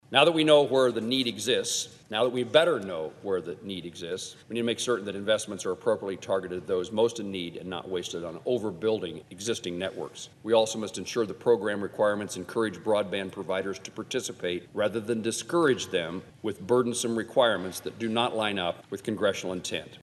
Sen. Jerry Moran pledged to continue oversight of federal broadband deployment programs. He spoke on the Senate floor earlier this month.